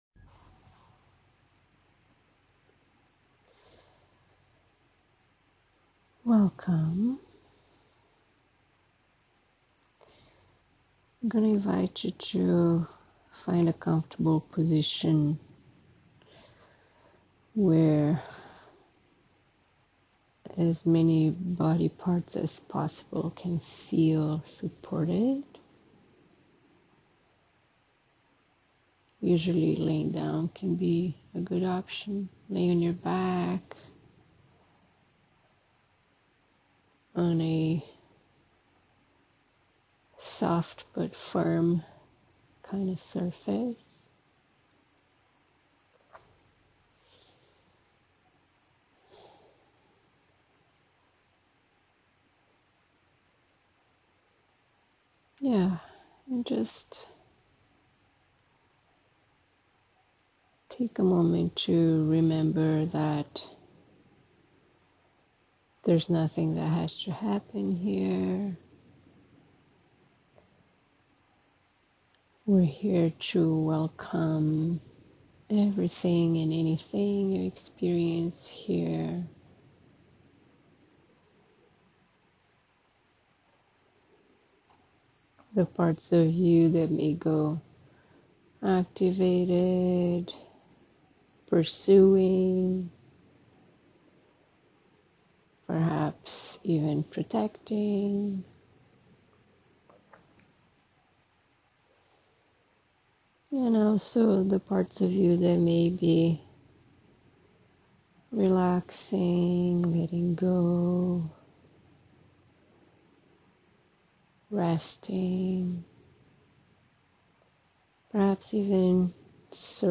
Here is a free audio recording of me guiding a self-massage session for you to experiment.